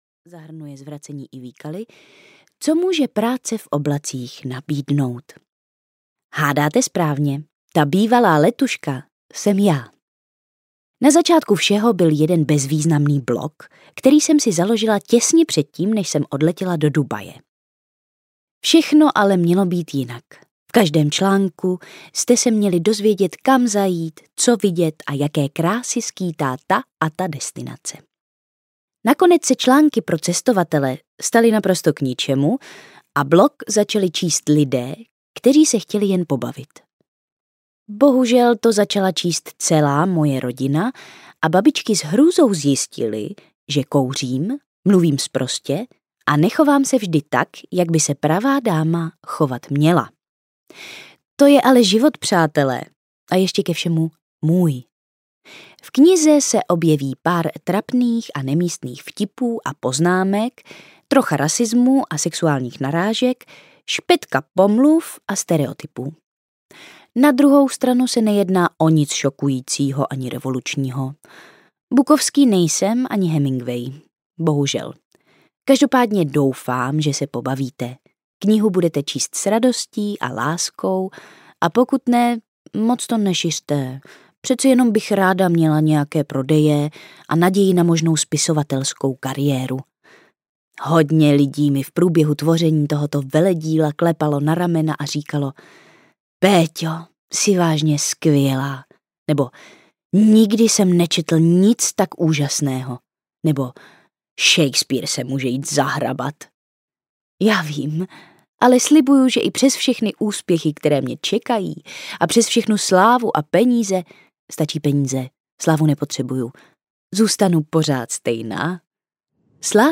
Letuška z economy audiokniha
Ukázka z knihy